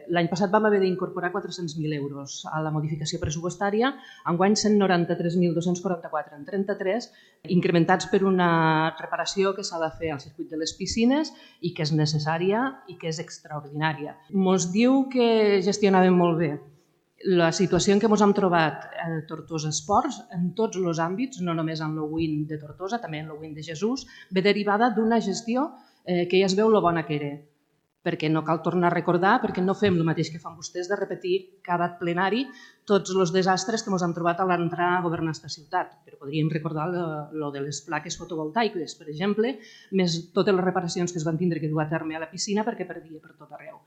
D’altra banda, el ple extraordinari ha aprovat dues modificacions pressupostàries, una de poc més de 229.000 euros per transferir aportacions a Tortosaesport i diferents entitats, amb el vot favorable de tots els grups i l’abstenció de Junts. El regidor de Junts per Tortosa, Òscar Ologaray, ha criticat la gestió del govern municipal mentre la regidora d’hisenda, Maria Jesús Viña, ha defensat l’aportació extraordinària i ha recordat el mal estat de les instal.lacions esportives amb què s’ha trobat l’executiu…
Viña-pleextr_modifcpressup_culturaiesports.mp3